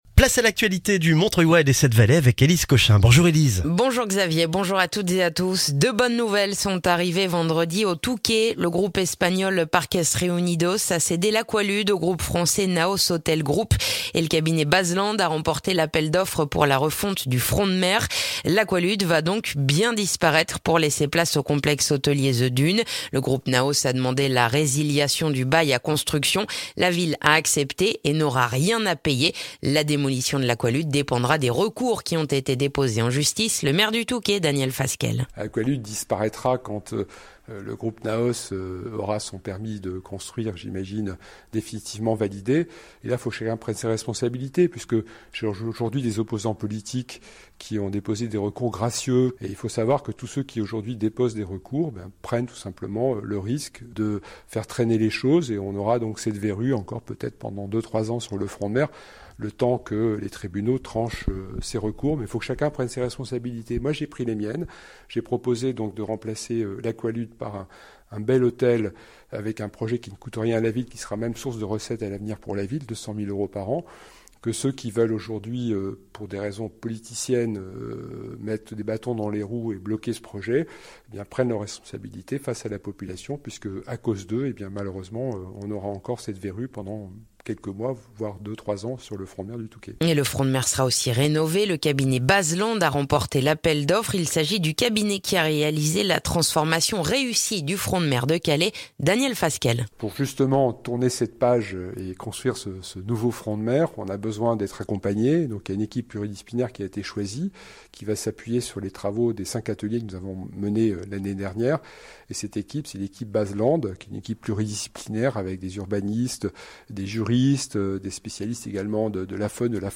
Le journal du lundi 6 mars dans le montreuillois